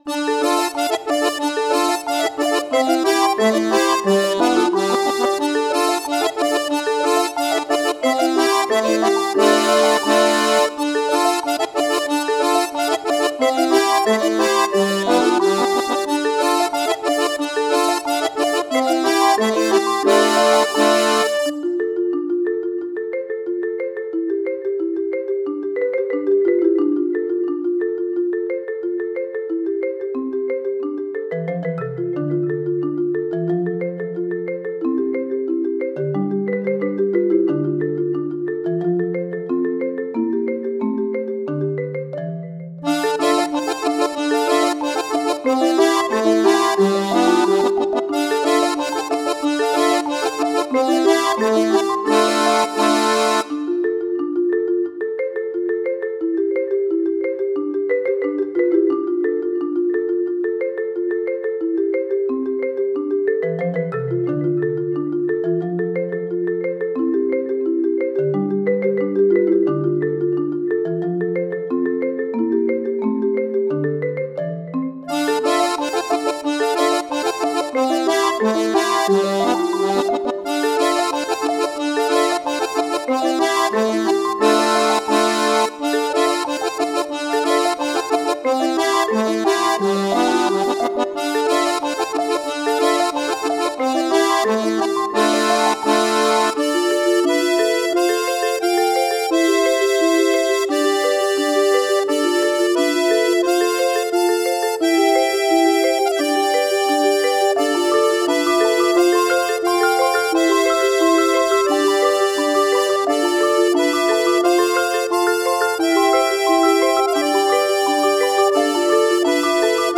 自制karaoke
非消音，听写的。
因为是平板弹的，所以并未试图完全复刻